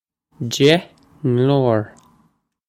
deich nglór jeh n'lowr
jeh n'lowr
This is an approximate phonetic pronunciation of the phrase.